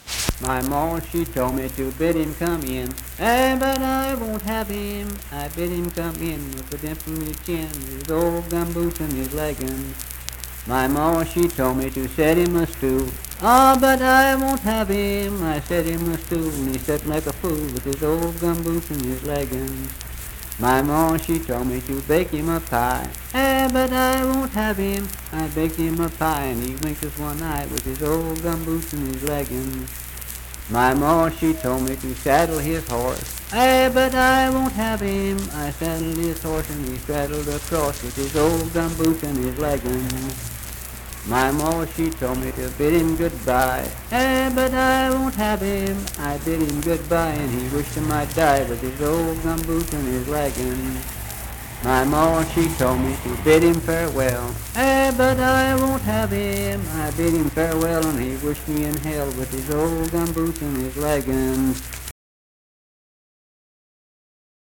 Unaccompanied vocal music performance
Verse-refrain 6(4w/R).
Dance, Game, and Party Songs, Children's Songs
Voice (sung)